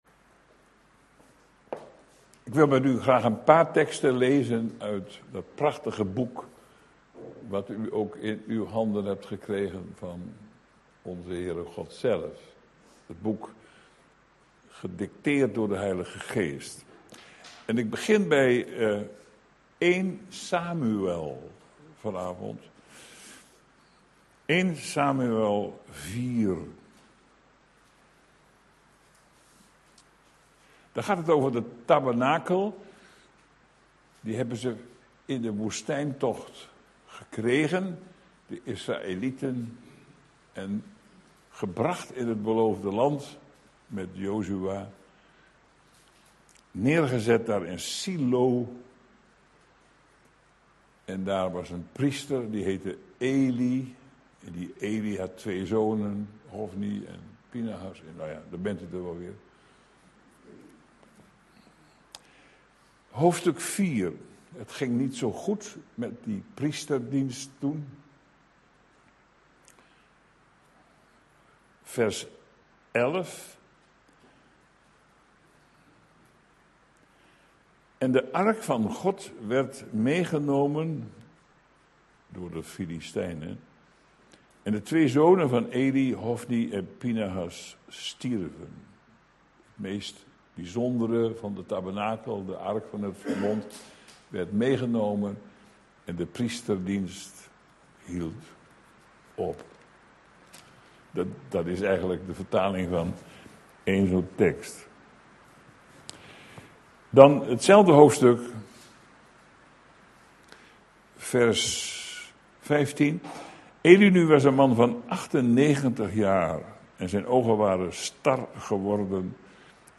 Deze preek is onderdeel van de serie: "Waar is de ware kerk?"